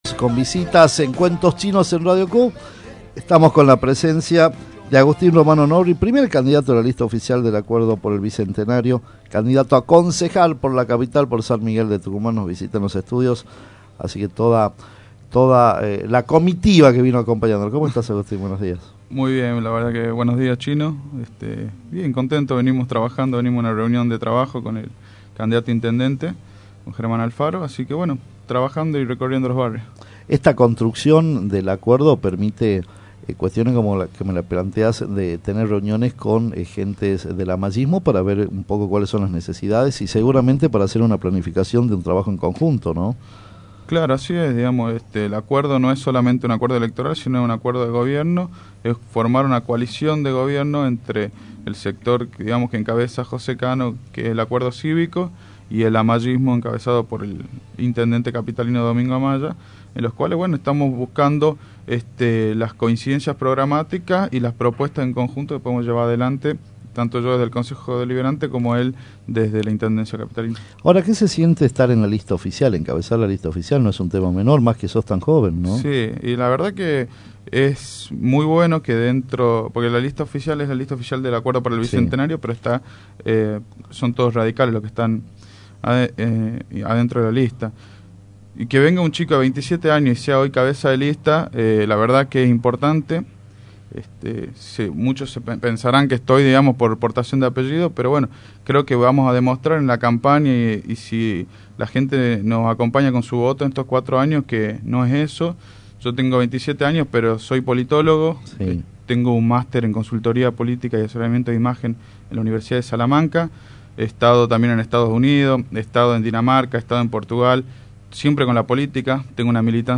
El candidato estuvo en los estudio de Radio Q y contó a la audiencia las propuestas. Capital necesita una policía municipal que controle y prevé el delito, sobre todo la seguridad del ciudadanos